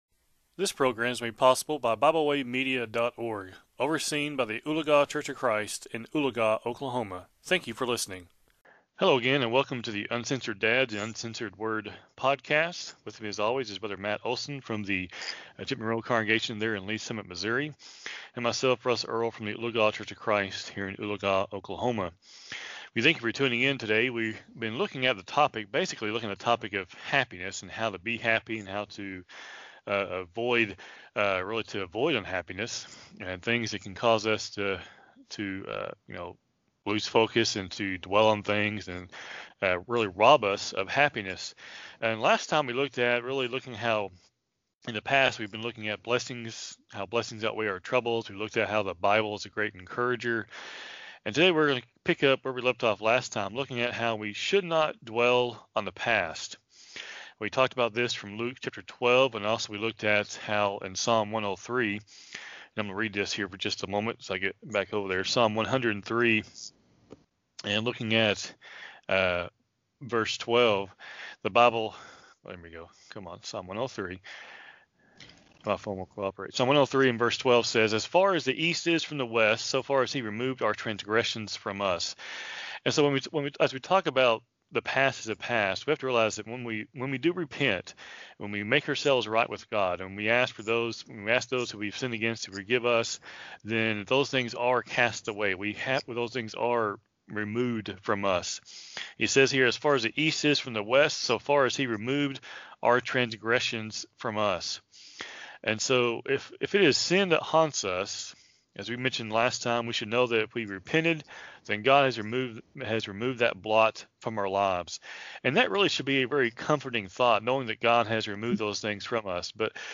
Program Info: Live program from the TGRN studio in Mount Vernon, TXClick here for current program schedule.